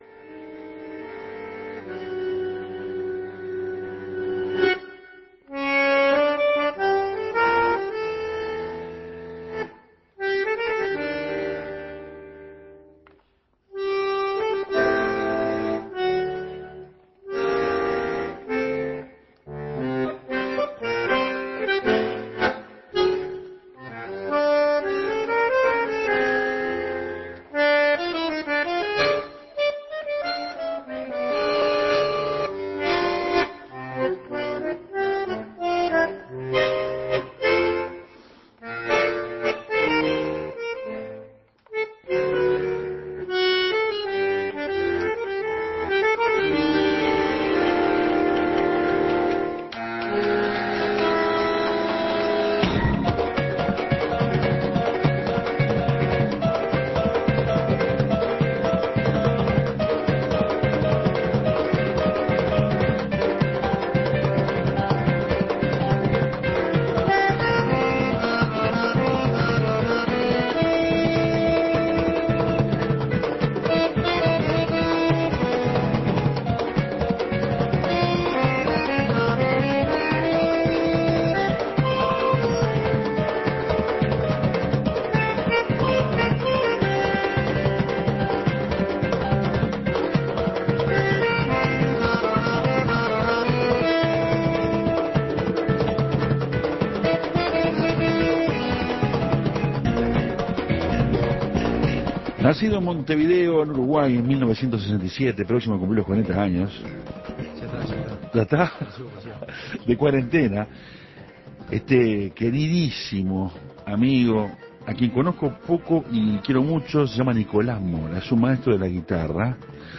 El gran guitarrista y cantante